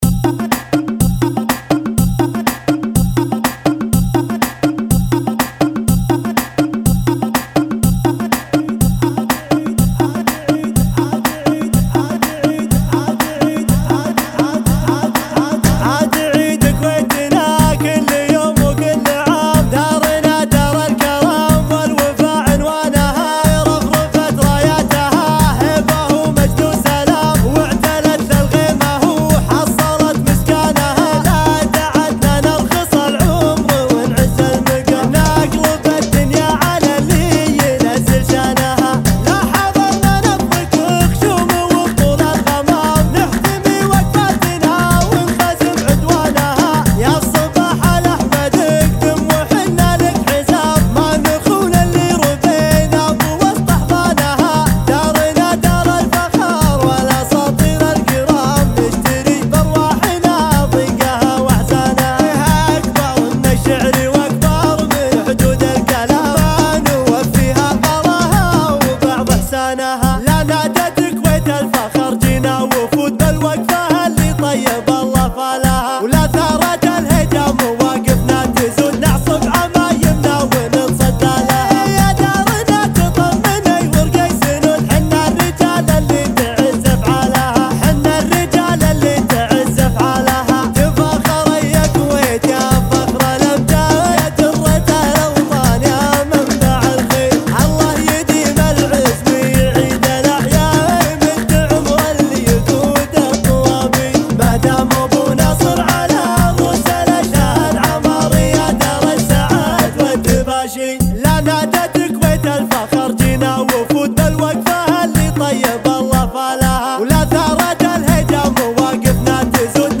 [ 123 bpm ]